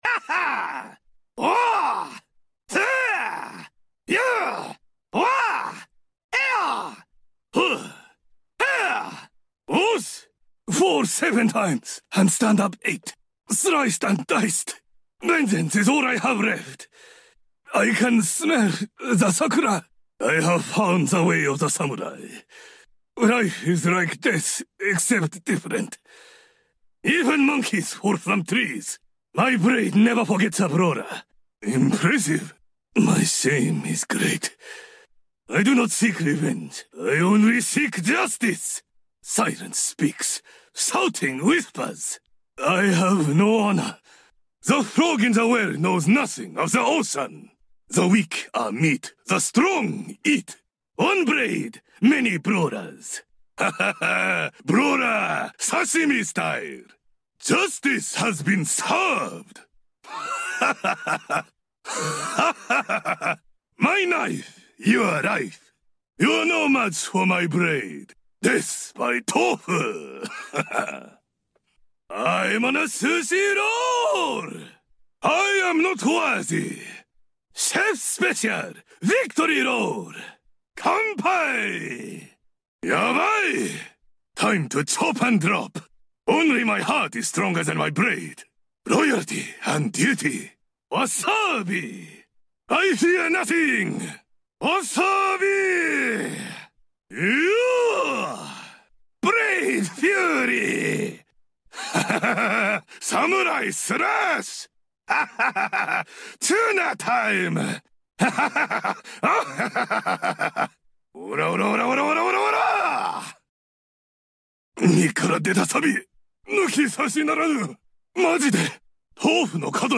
All Kenji voice lines ! sound effects free download